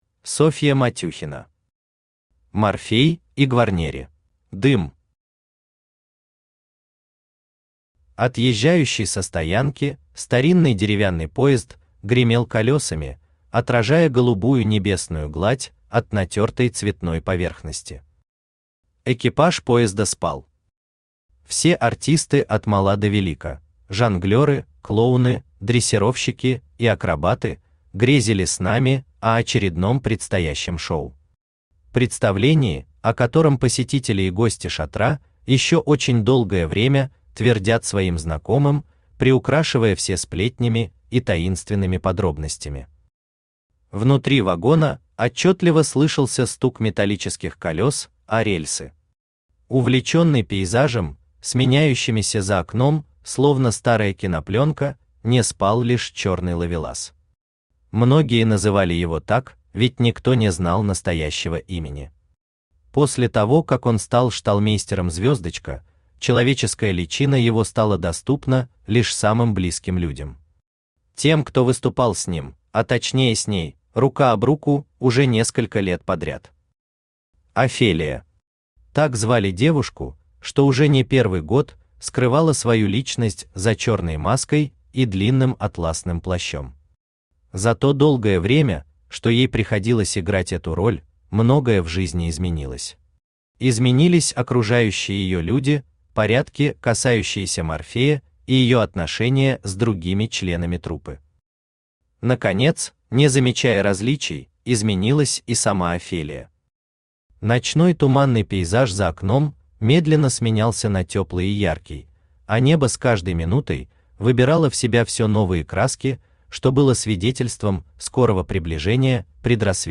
Аудиокнига Морфей и Гварнери | Библиотека аудиокниг
Aудиокнига Морфей и Гварнери Автор Софья Матюхина Читает аудиокнигу Авточтец ЛитРес.